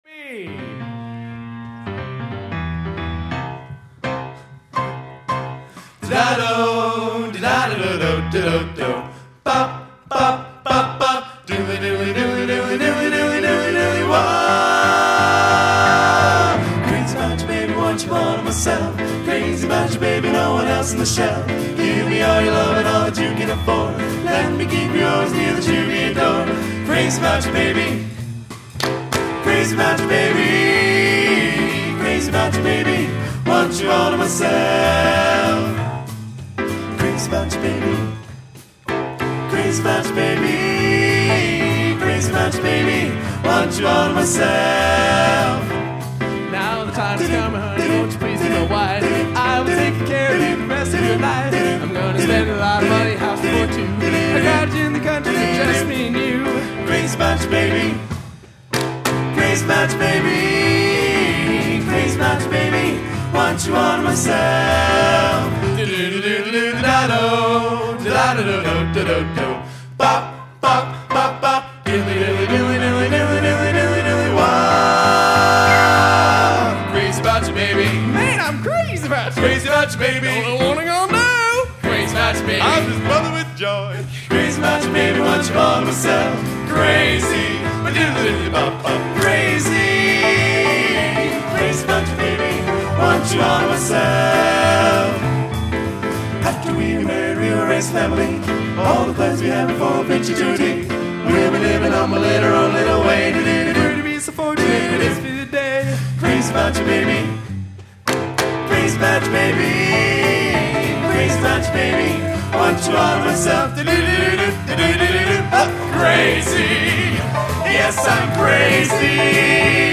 Collection: Northrop High School 10/21/2001
Location: Northrop High School, Fort Wayne, Indiana